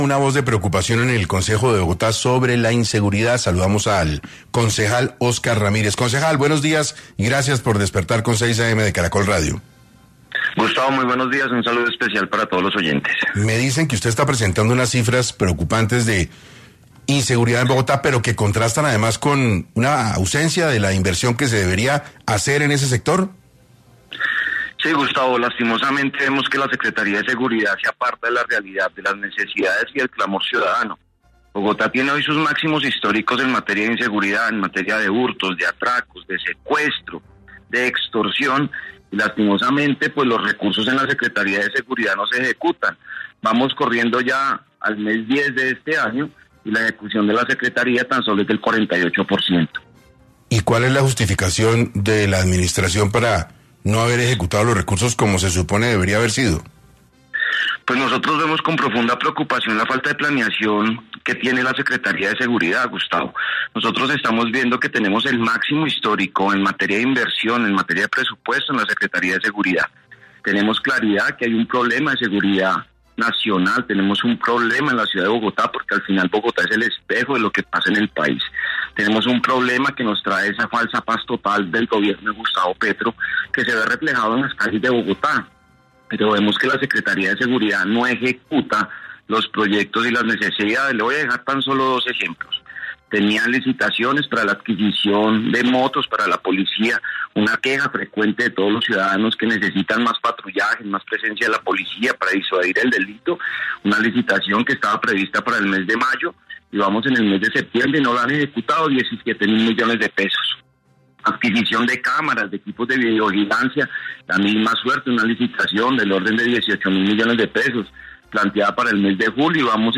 El concejal de Bogotá, Óscar Vahos, habló en 6AM sobre la gestión de la Secretaría de Seguridad, cuya ejecución no supera el 48%.